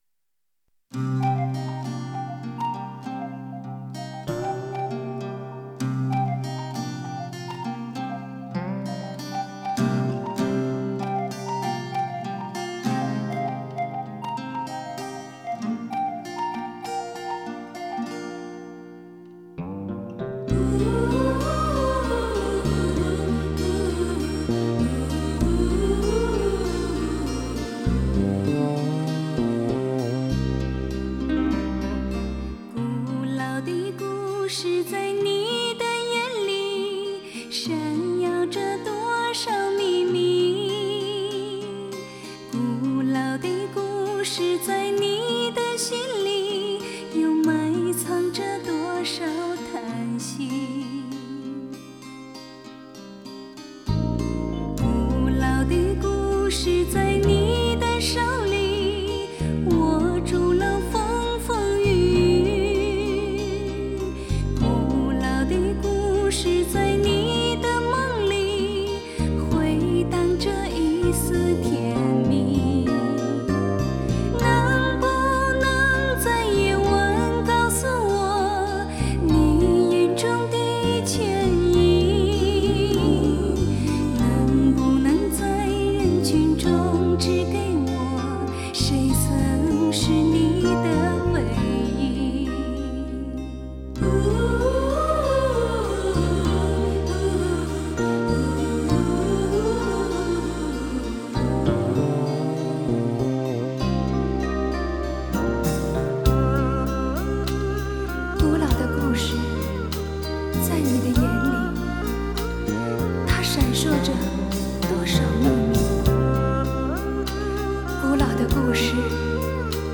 类别: 流行